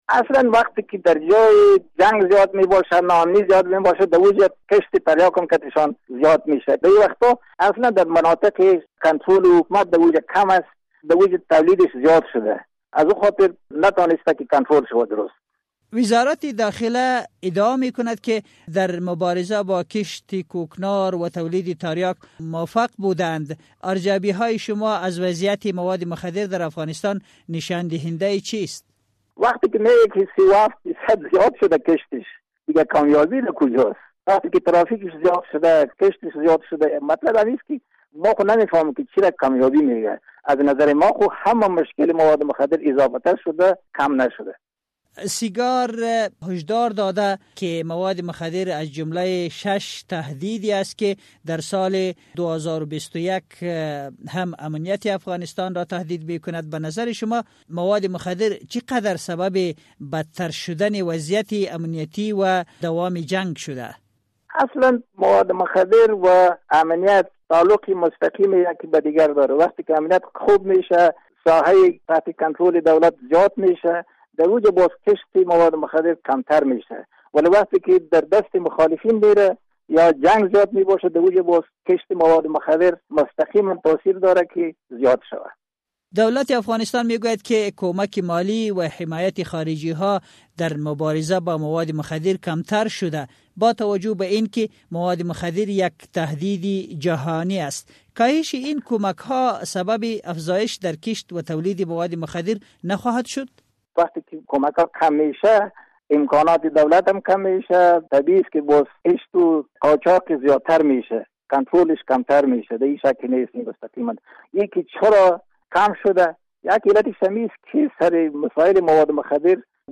گفتگو با داکتر ظفر، معین پیشین وزارت مبارزه با مواد مخدر